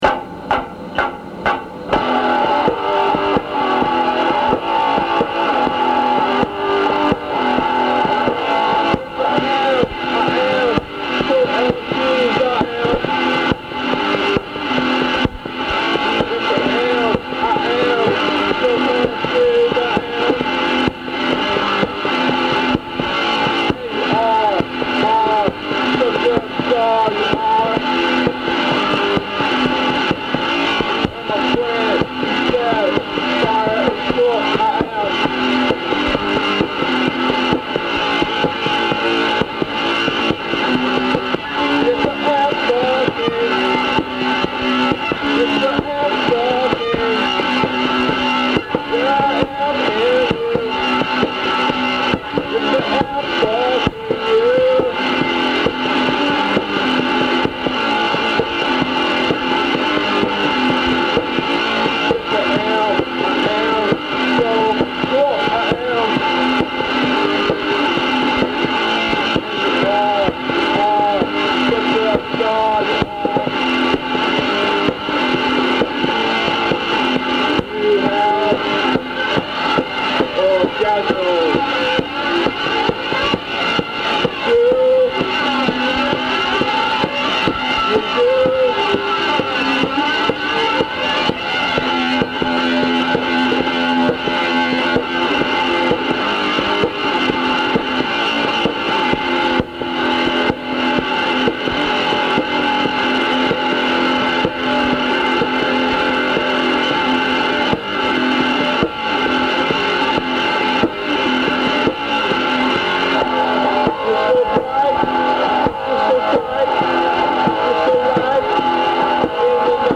Maybe it’s just the guitar sounds. It’s all over the place.